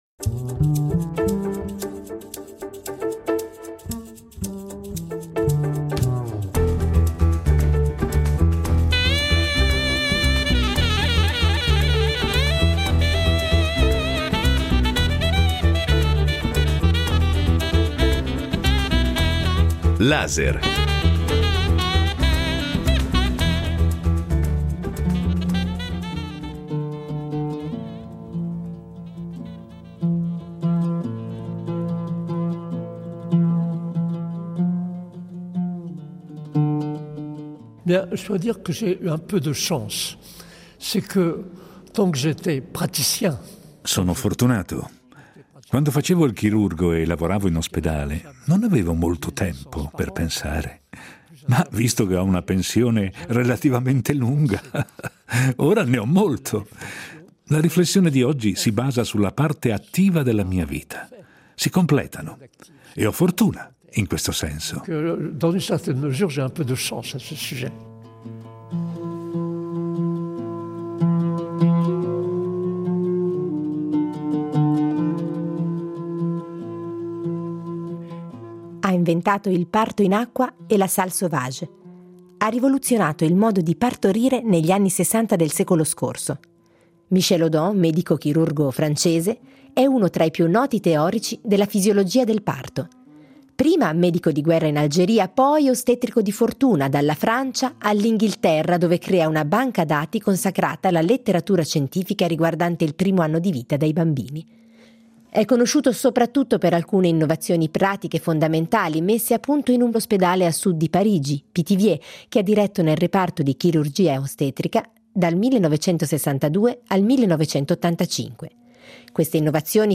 Intervista al medico chirurgo
Intervallano questo incontro alcuni spezzoni tratti da Nati in casa , monologo del 2001 scritto da Giuliana Musso (Premio Hystrio alla drammaturgia 2017) e Massimo Somaglino.